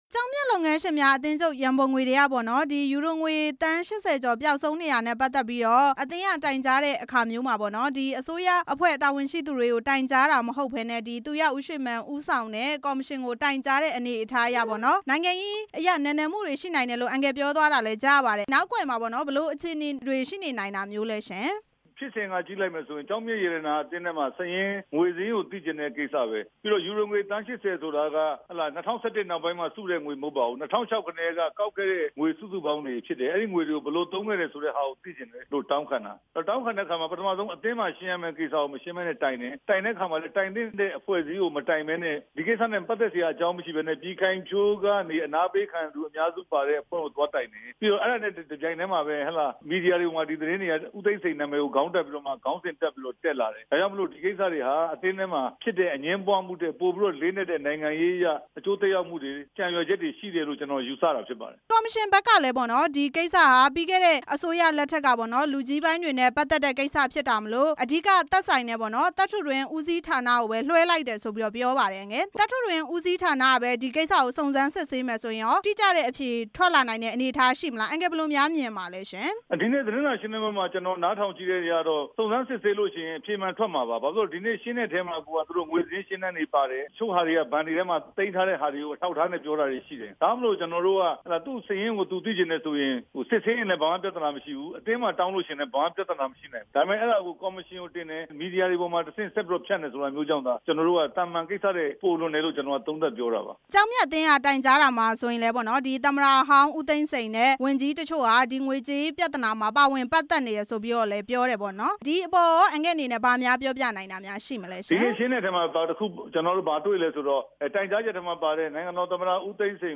နေပြည်တော် ဇမ္ဗူသီရိမြို့နယ်က ကျောက်မျက်သင်တန်းကျောင်းမှာ ဒီကနေ့ ကျင်းပတဲ့ သတင်းစာရှင်းလင်းပွဲကို ဝန်ကြီးဟောင်း ဦးရဲထွဋ်လည်း တက်ရောက်ခဲ့တာဖြစ်ပြီး ရှင်းလင်းပွဲအပြီးမှာ ဦးရဲထွဋ် ကသတင်းထောက်တွေ ကို ပြောခဲ့တာဖြစ်ပါတယ်။